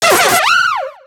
Cri de Rototaupe dans Pokémon X et Y.